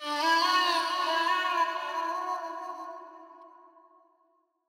EDMChant.wav